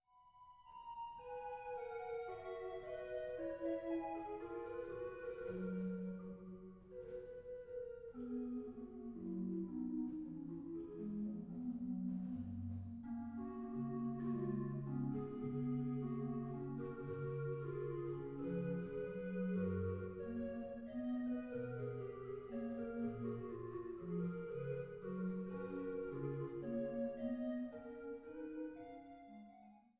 Zöblitz